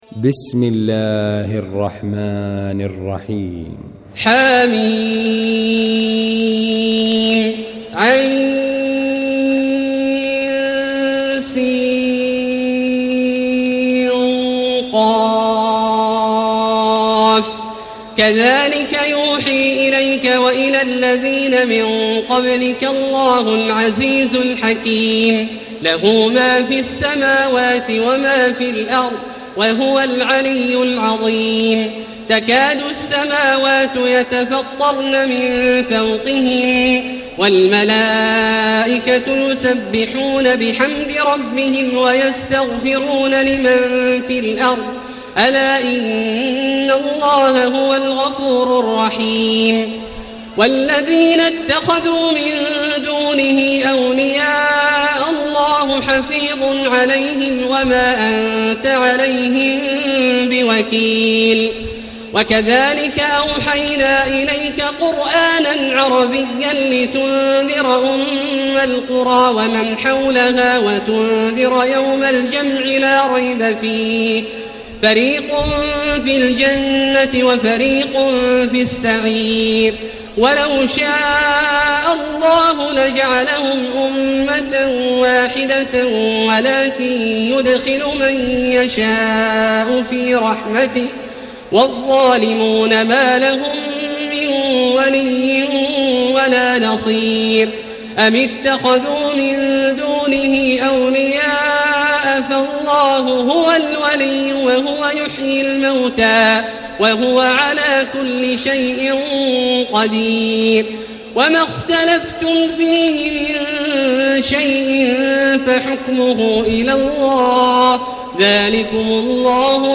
المكان: المسجد الحرام الشيخ: فضيلة الشيخ عبدالله الجهني فضيلة الشيخ عبدالله الجهني فضيلة الشيخ ماهر المعيقلي الشورى